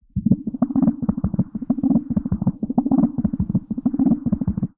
Filtered Feedback 11.wav